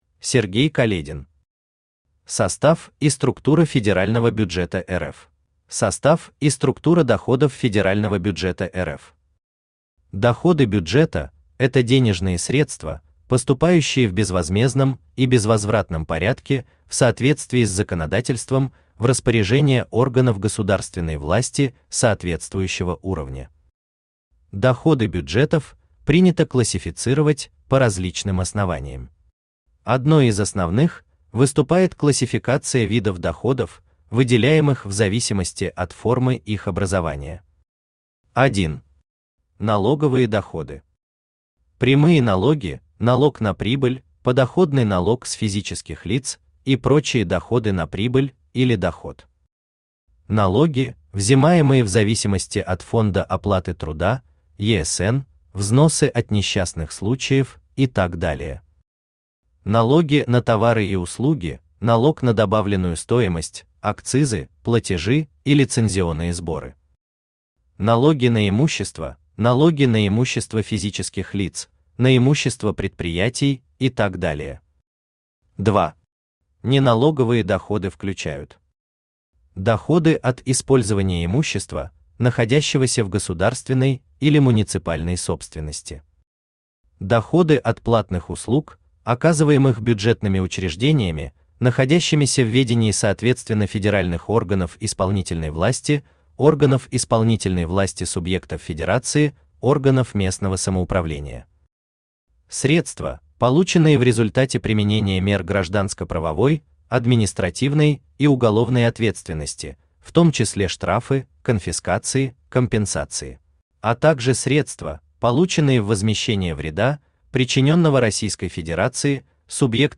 Аудиокнига Состав и структура федерального бюджета РФ | Библиотека аудиокниг
Aудиокнига Состав и структура федерального бюджета РФ Автор Сергей Каледин Читает аудиокнигу Авточтец ЛитРес.